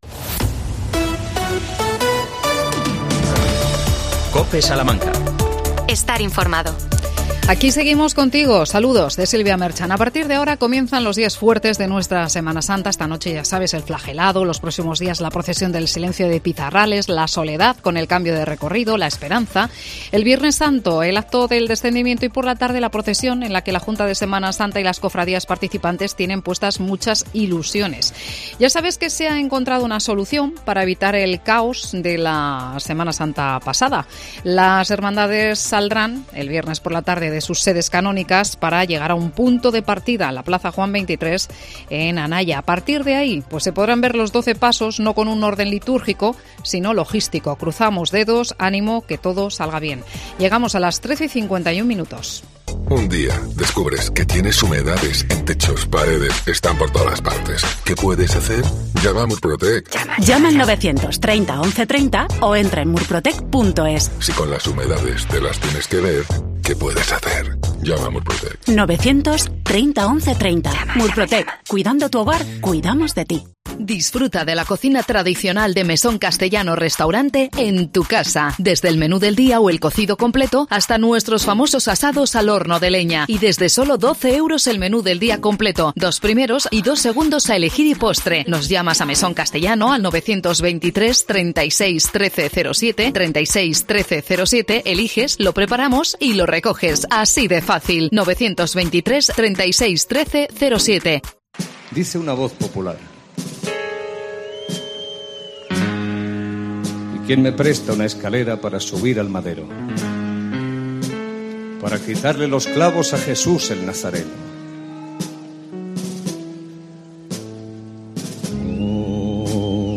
40 años de la representación de La Pasión en Serradilla del Arroyo. Entrevistamos a su alcalde Manuel Montero.